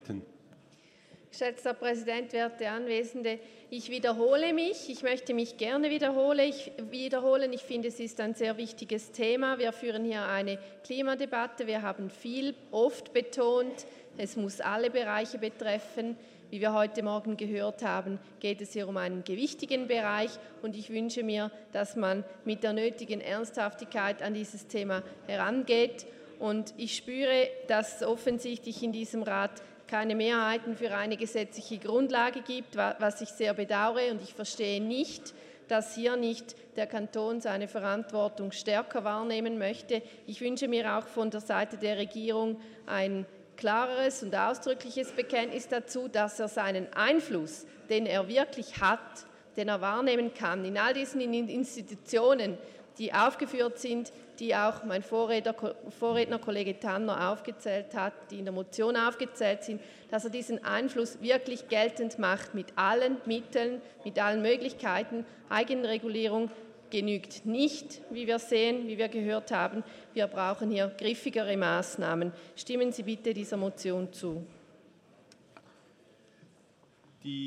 13.6.2019Wortmeldung
Session des Kantonsrates vom 11. bis 13. Juni 2019